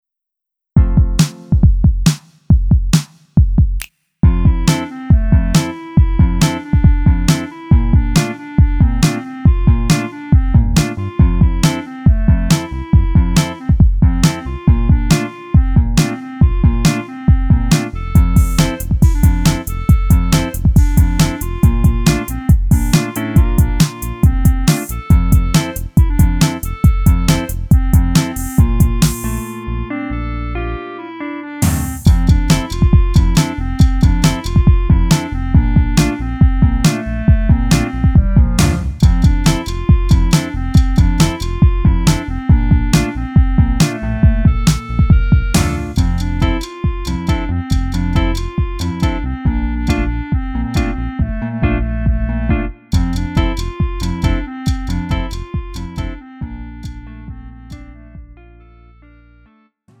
음정 원키 2:11
장르 가요 구분 Lite MR